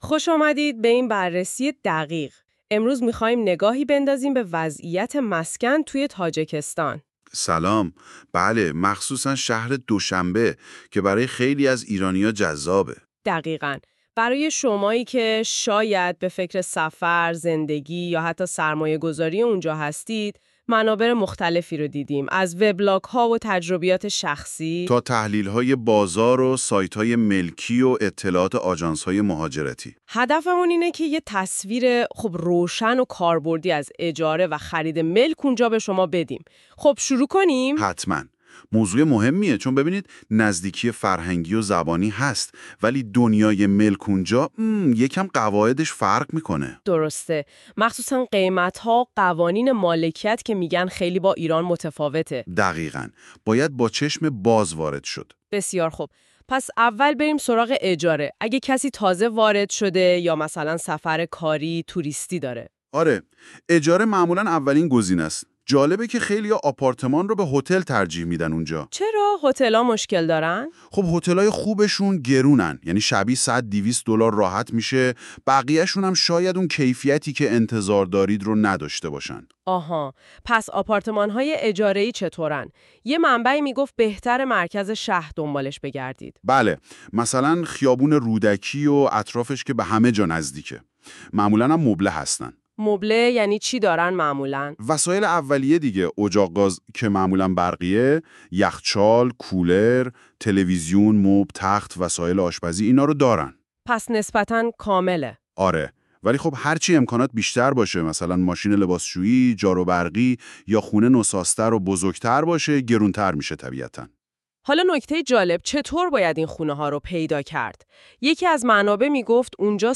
پادکست خرید خانه در تاجیکستان: